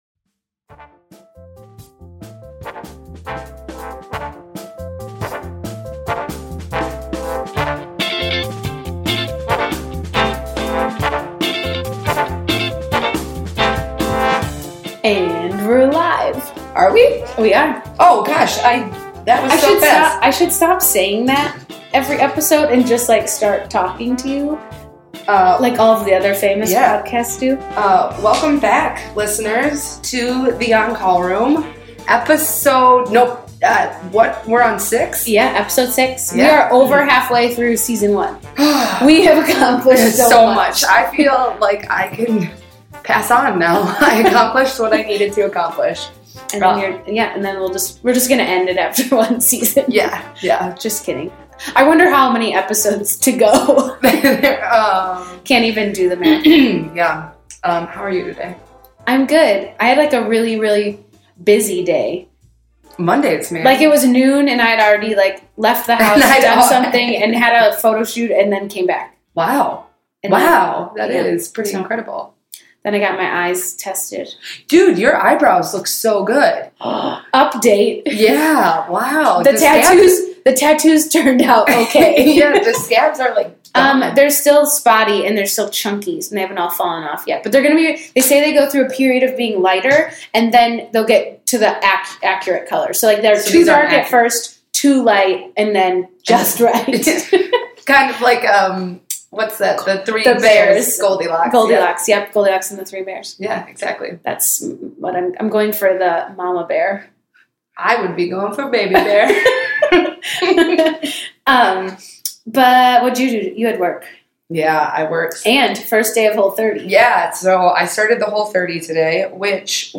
The three girls discuss what happened the previous evening when they went out to a bar - and are wondering why they don’t get hit on by any McDreamy’s. They discuss the relationship traits of Derek and Burke - and wonder why they are so much more needy than the women they are with.